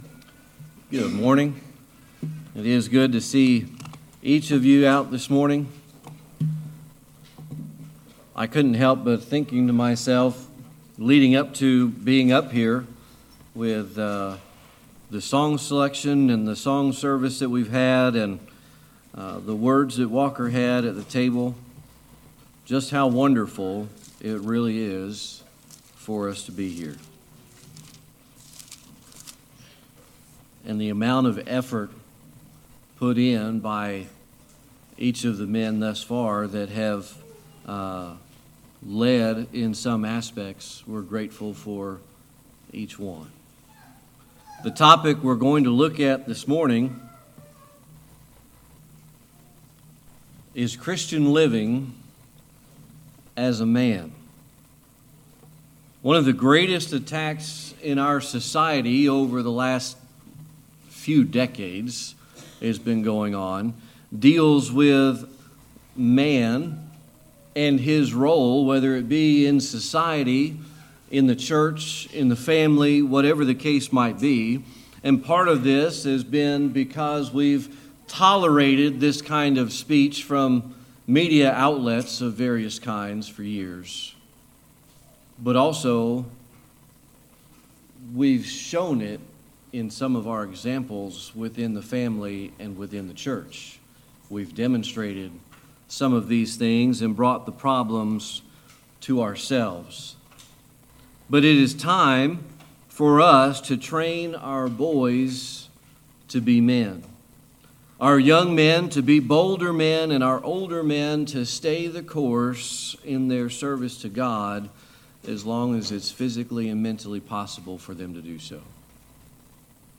1 John 2:14 Service Type: Sunday Morning Worship The topic we're going to look at this morning is Christian Living